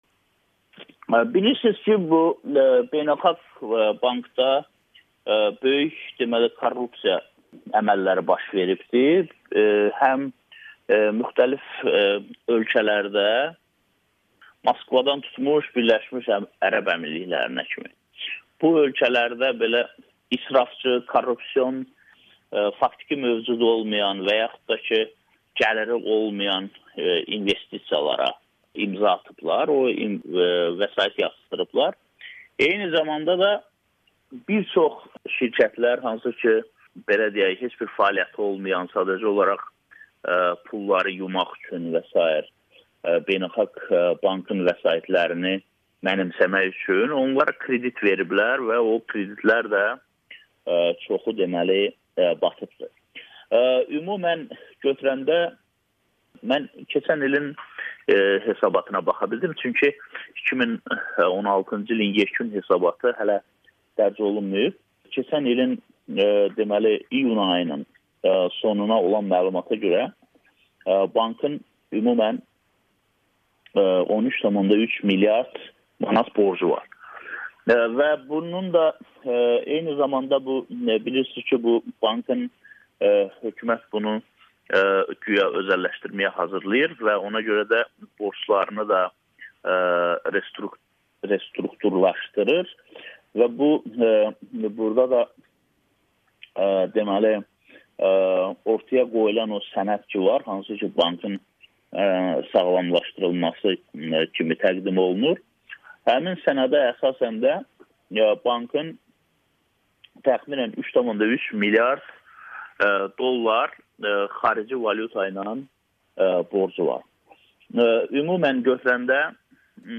Amerikanın Səsinə müsahibə verib.